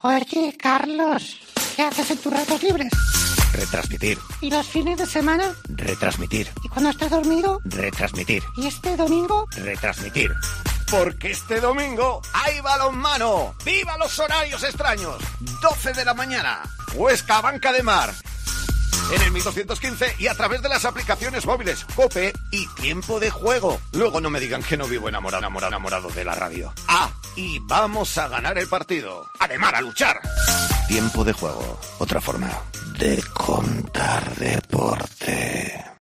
Escucha la cuña promocional del partido Huesca - Abanca Ademar el día 14-11-21 a las 12:00 h en el 1.215 OM